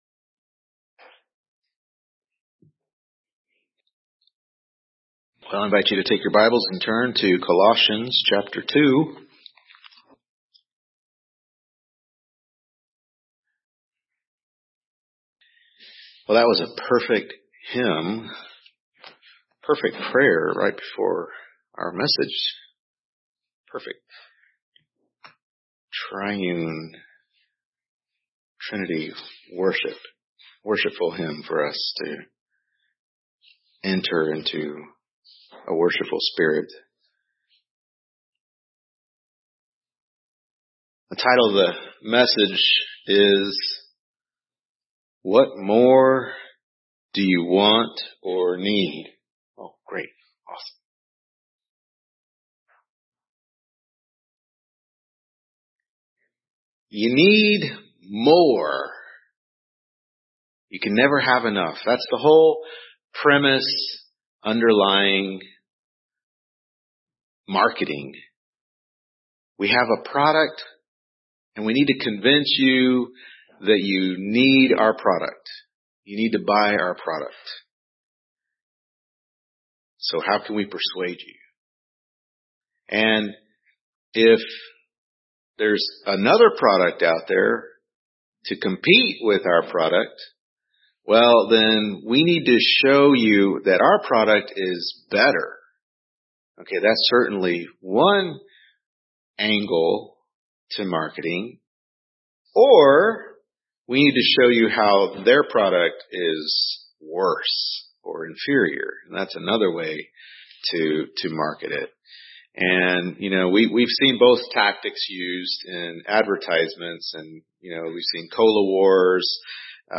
Colossians 2:9-10 Service Type: Morning Worship Service Colossians 2:9-10 What More Do You Do Want or Need? Topics: Filled in Christ , Fullness of Christ , Supremacy of Christ « Colossians 2:8 “Don’t Be Taken Captive!”